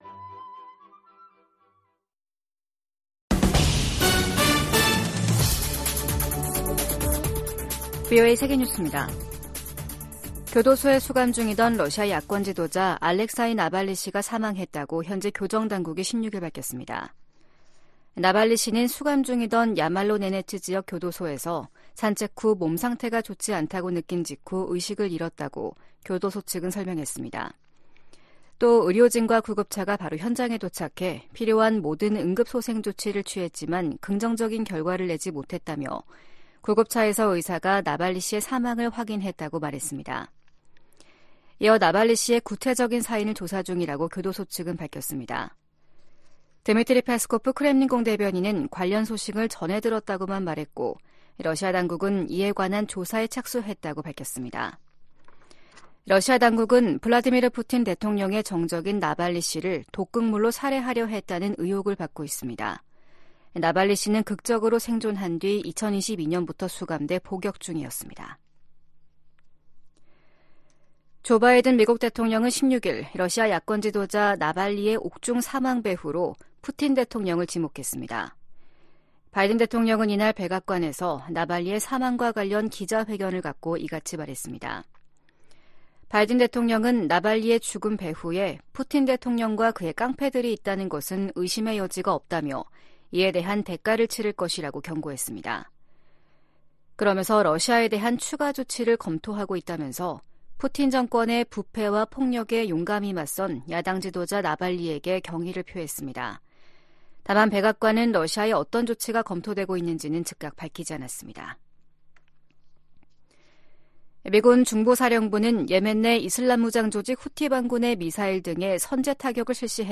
VOA 한국어 아침 뉴스 프로그램 '워싱턴 뉴스 광장' 2024년 2월 17일 방송입니다. 미국 고위 당국자들이 북한-러시아 관계에 우려를 나타내며 국제 협력의 중요성을 강조했습니다. 김여정 북한 노동당 부부장은 일본 총리가 평양을 방문하는 날이 올 수도 있을 것이라고 말했습니다.